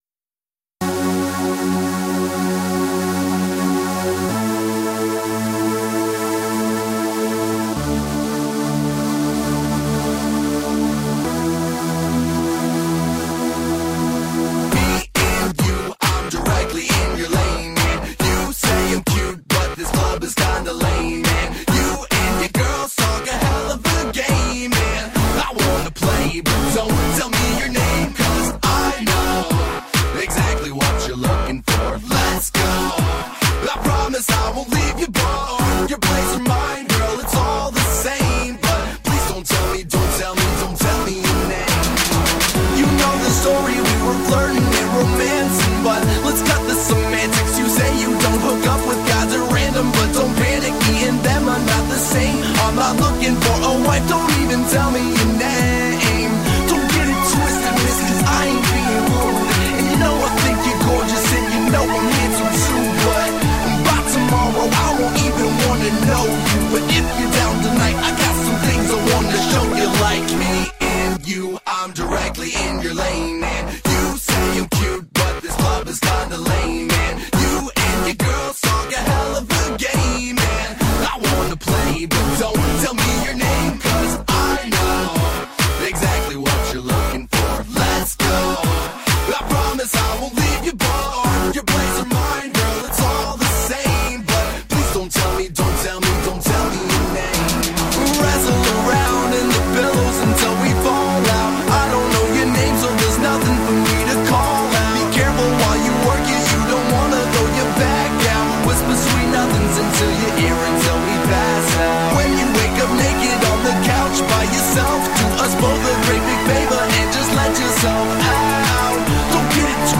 Uptempo Pop/Dance tune with a Hip Hop overtone.